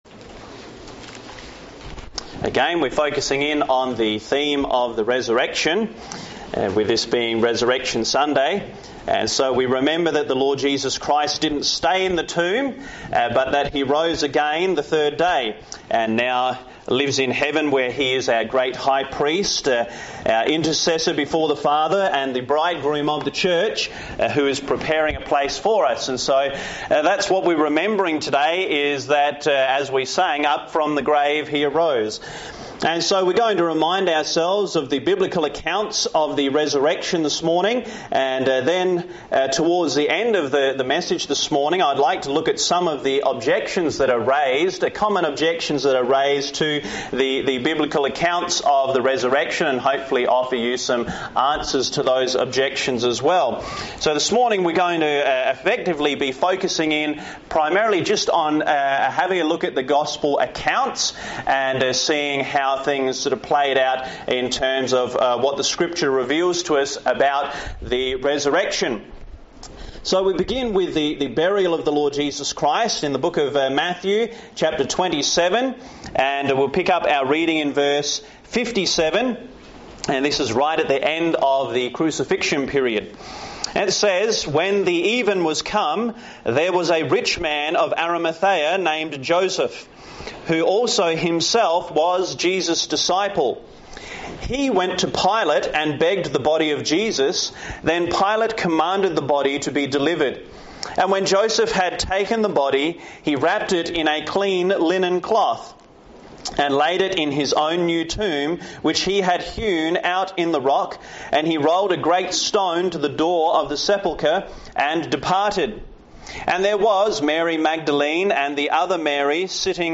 This sermon examines the burial and resurrection of Jesus Christ, the discovery of the empty tomb, the witnesses who confirmed it, and the objections raised against it. The message shows that Christ truly died, truly rose again, and now offers eternal life to all who believe.